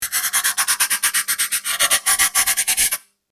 Pencil Writing Asmr Sound Button - Free Download & Play